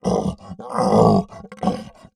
MONSTER_Effort_05_mono.wav